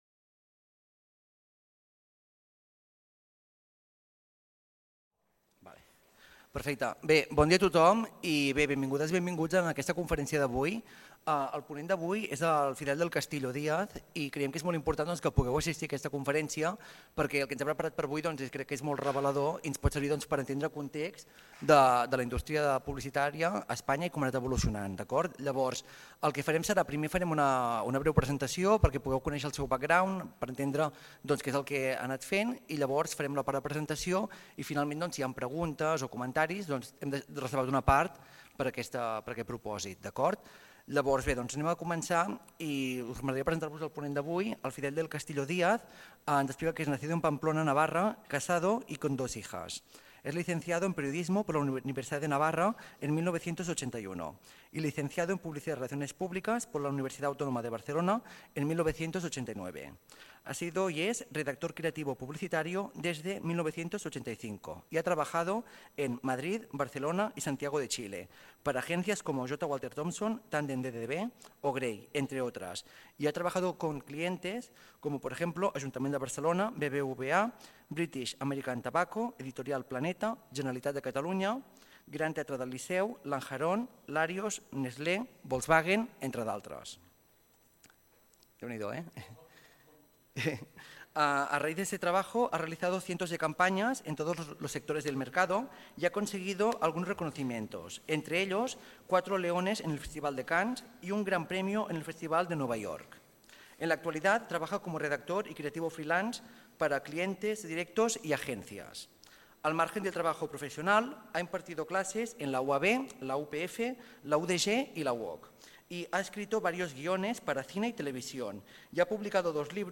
Conferència